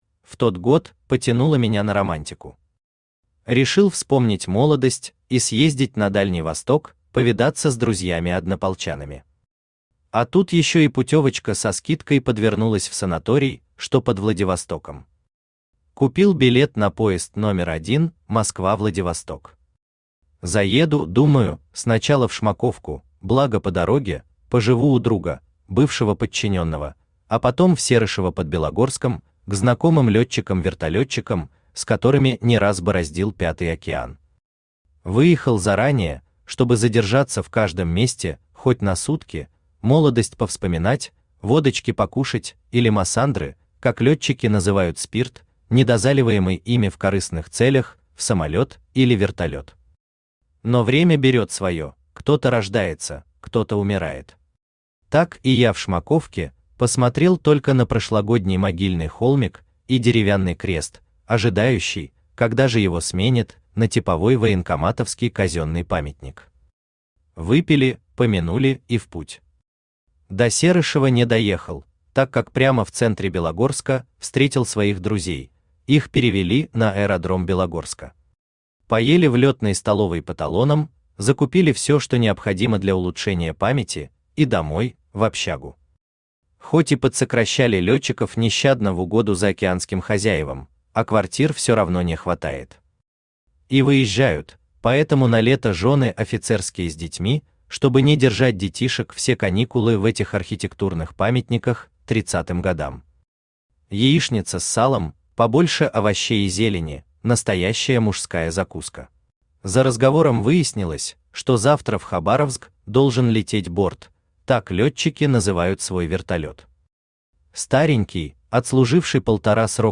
Aудиокнига Летнее путешествие по тайге Автор Владимир Мищенко Читает аудиокнигу Авточтец ЛитРес.